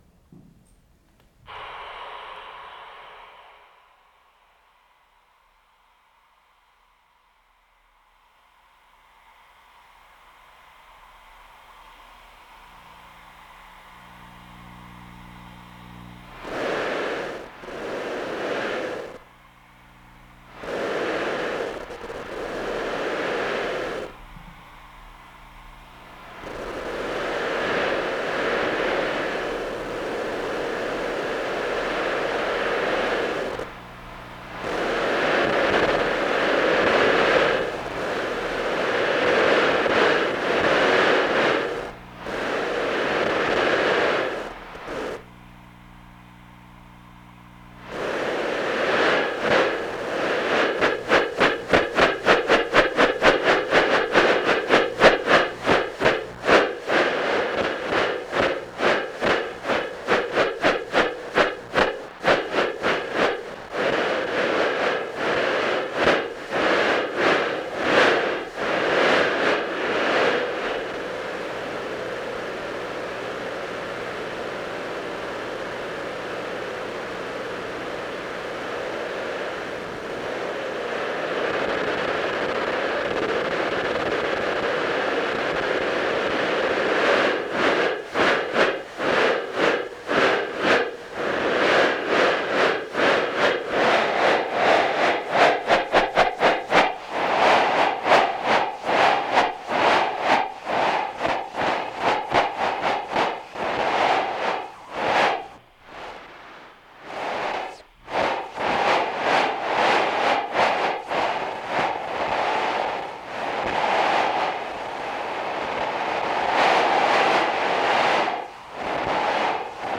plus explorations of voice and electronics.